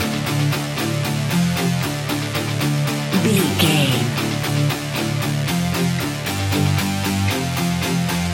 energetic, powerful and aggressive hard rock track
Fast paced
In-crescendo
Ionian/Major
industrial
groovy
dark